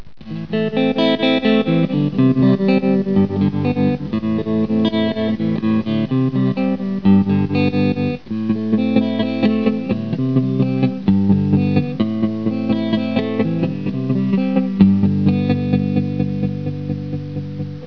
TREMOLO TEXT t
tremolo.wav